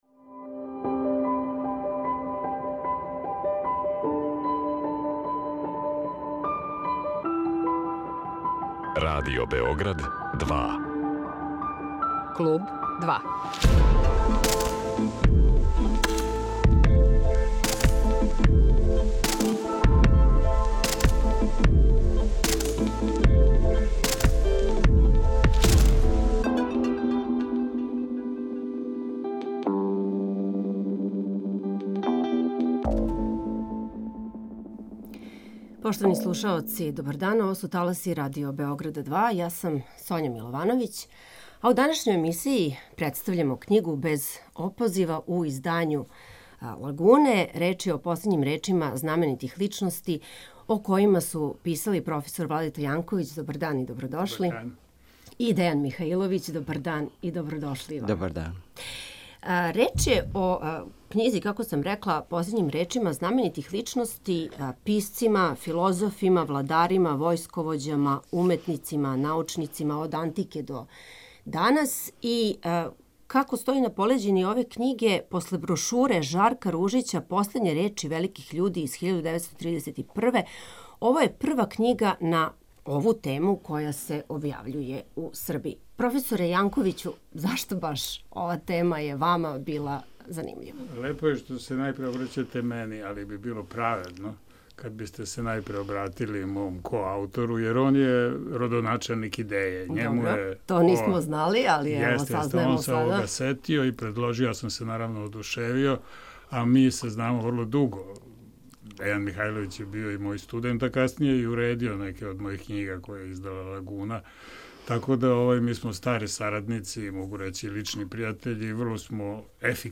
O nameri da sačine ovu zbirku, načinu na koji su tekstovi nastajali, poukama koje sadrže i njihovoj aktuelnosti u „Klubu 2“ razgovor je vođen sa autorima knjige „Bez opoziva“.